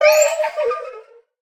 Minecraft Version Minecraft Version 1.21.5 Latest Release | Latest Snapshot 1.21.5 / assets / minecraft / sounds / mob / allay / item_given2.ogg Compare With Compare With Latest Release | Latest Snapshot
item_given2.ogg